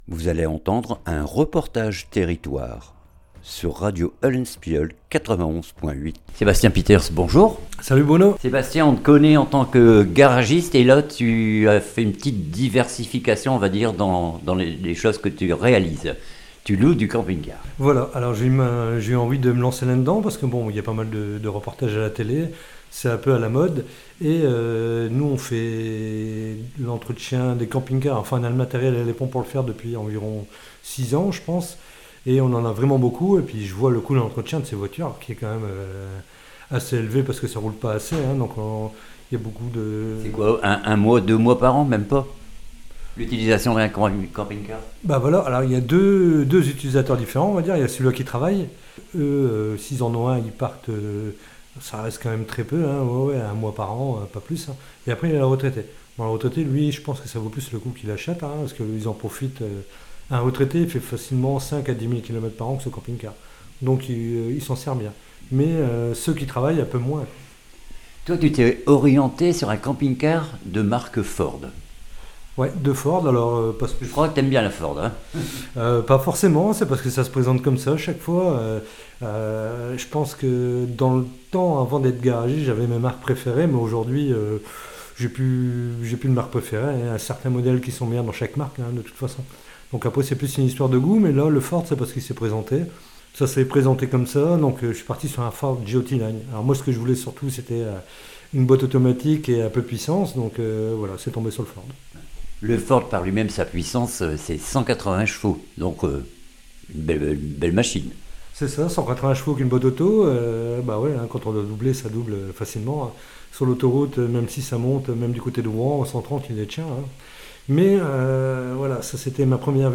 REPORTAGE TERRITOIRE LOCATION GARAGE PIETERS CAMPING CAR